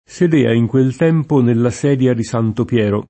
sedia [S$dLa] s. f. — antiq. nei vari sign. di sede (propri e fig.): sedea in quel tempo nella sedia di Santo Piero [